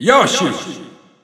Announcer saying Yoshi's name in Italian from Super Smash Bros. 4 and Super Smash Bros. Ultimate
Yoshi_Italian_Announcer_SSB4-SSBU.wav